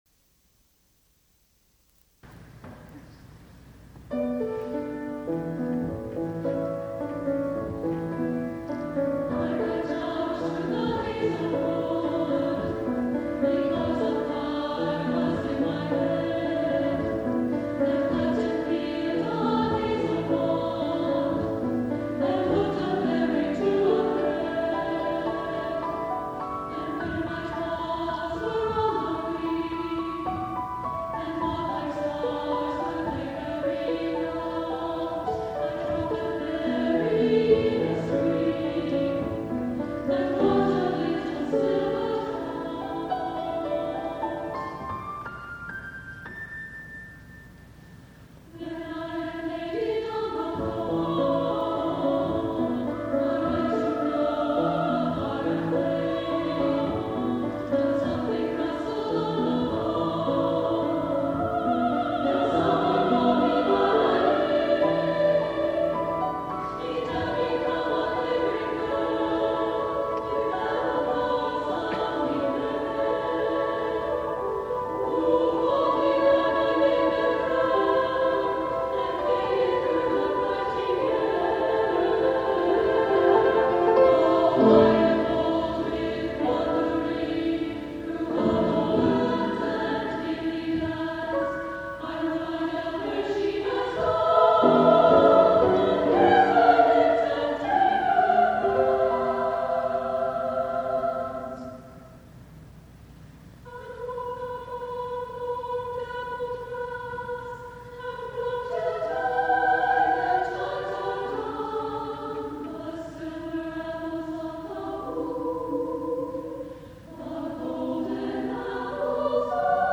for SSA Chorus and Piano (1986)